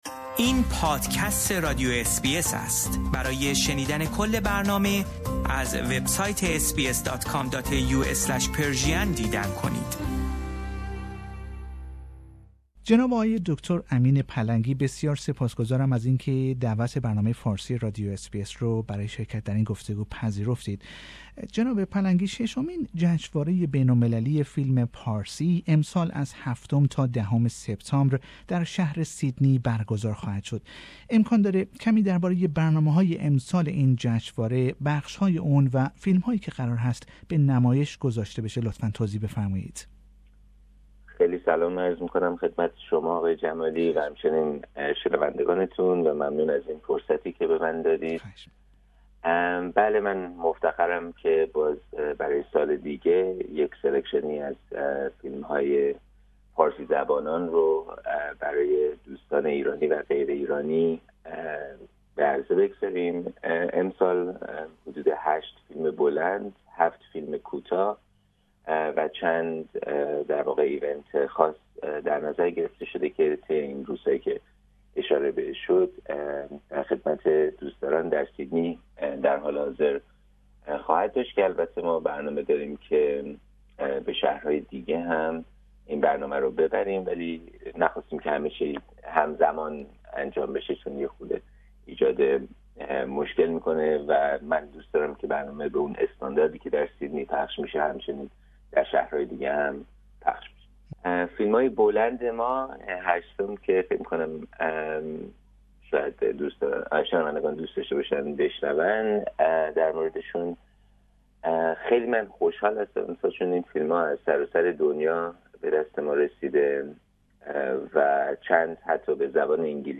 در گفتگو با بخش فارسی رادیو اس بی اس درباره این جشنواره و برنامه های آن سخن می گوید.